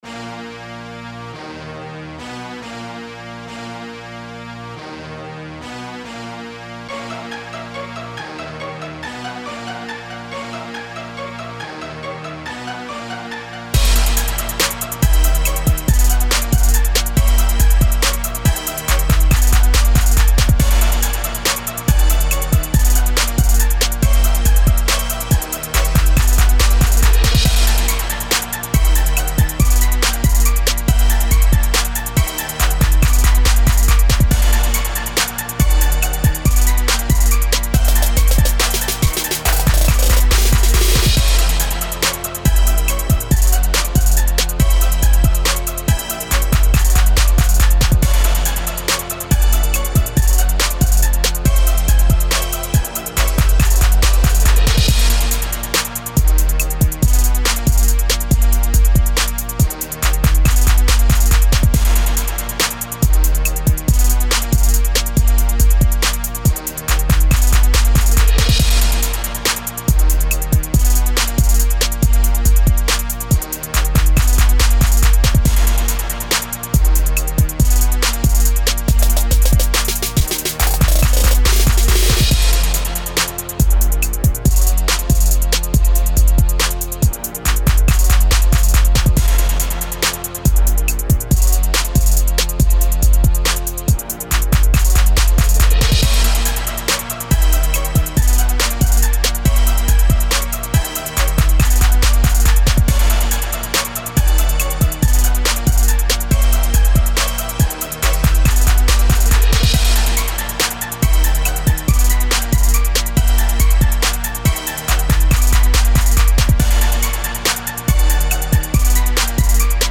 This is a slow down south party joint.